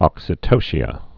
(ŏksĭ-tōshē-ə, -shə)